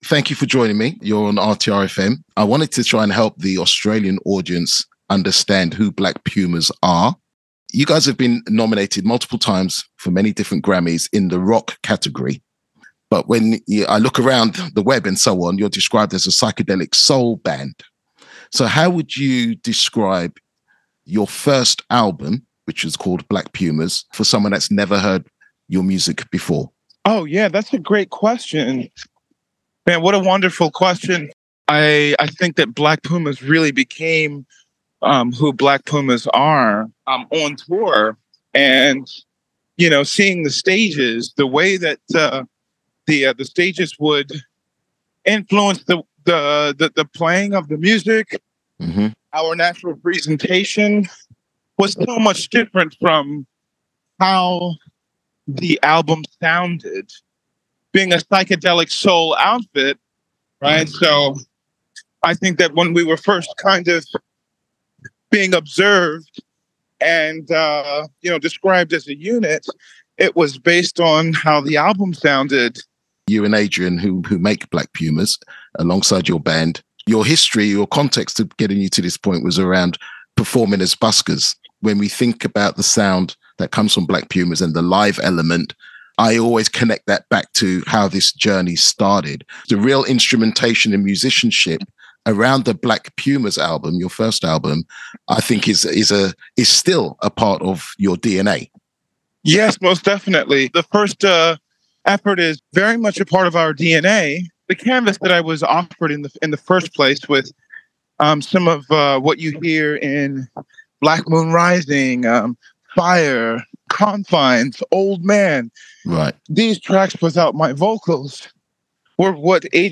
black-pumas-interview.mp3